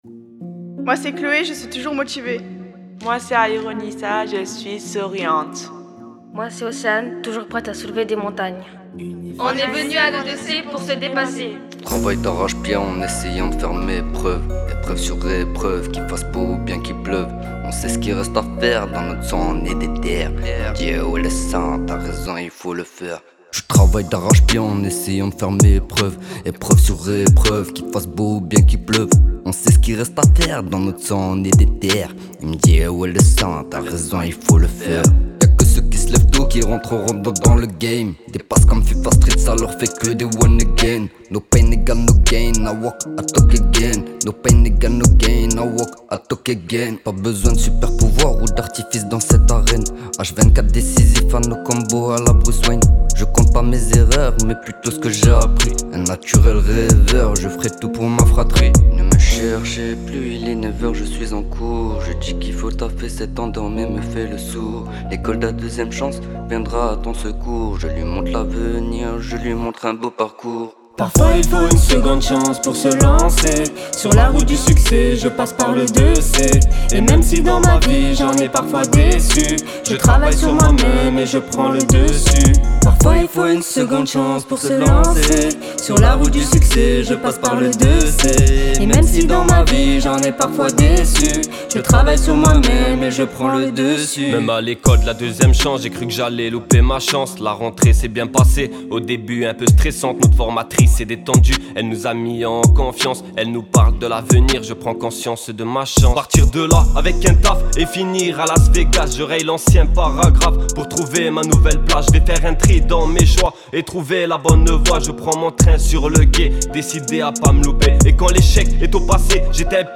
Les jeunes de l’E2C de Forbach se lancent à nouveau dans un projet ambitieux et créatif intitulé “E2C en un Chœur”. Ce projet vise à écrire, composer et enregistrer une chanson originale, reflétant leurs expériences, leurs aspirations et leur énergie collective.